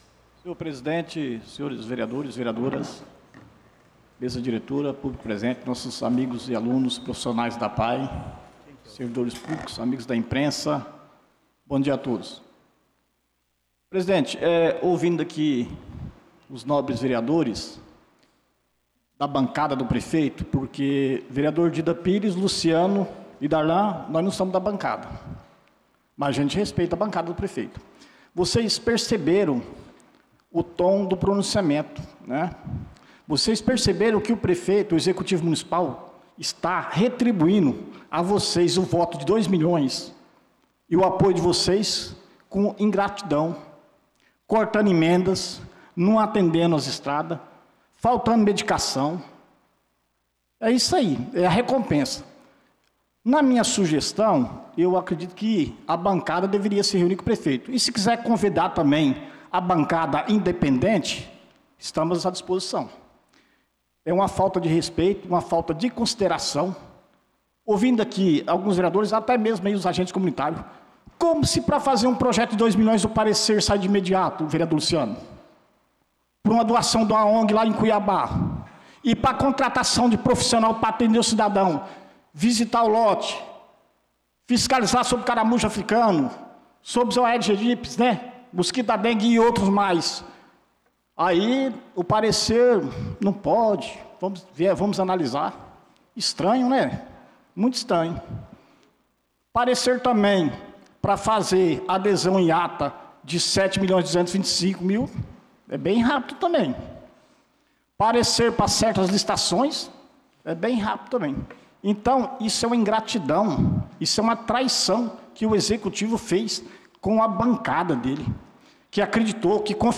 Pronunciamento do vereador Dida Pires na Sessão Ordinária do dia 05/05/2025